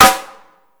Sn (Oldschool).wav